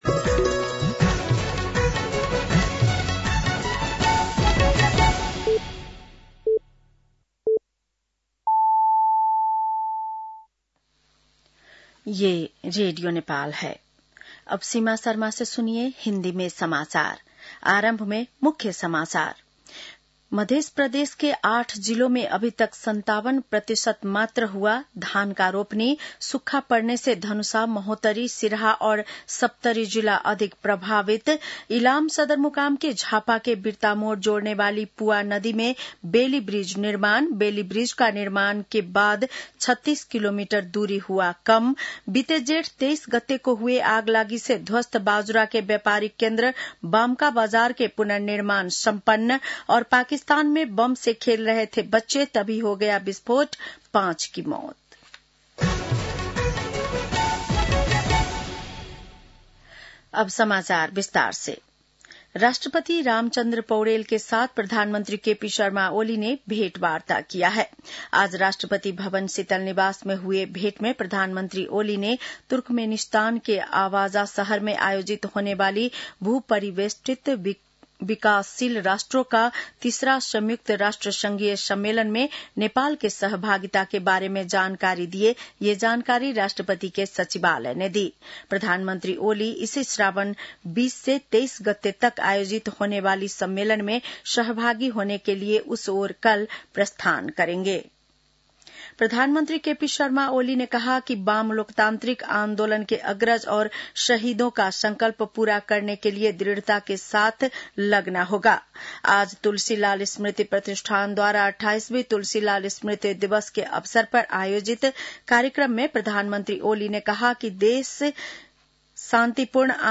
बेलुकी १० बजेको हिन्दी समाचार : १७ साउन , २०८२
10-PM-hindi-NEWS-.mp3